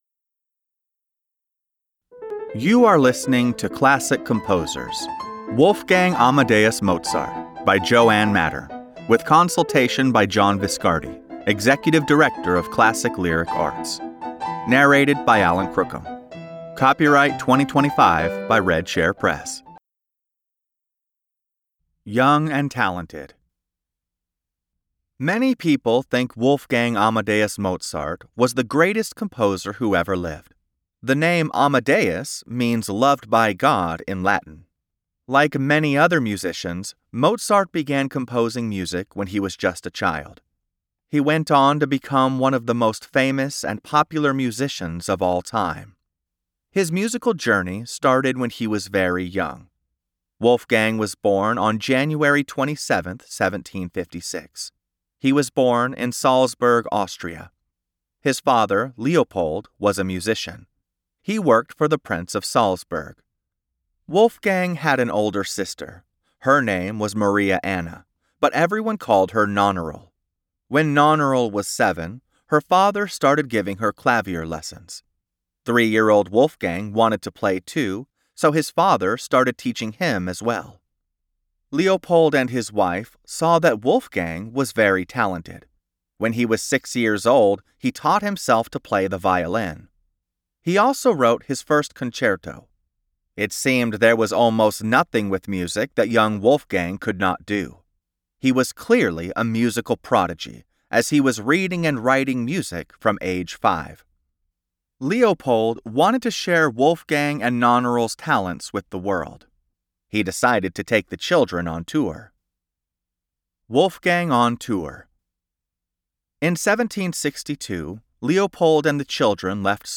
Reading Wolfgang Amadeus Mozart (Classical Composers) by Joanne Mattern (Author), Marilena Perilli (Illustrator) | MagicBlox Online Kid's Book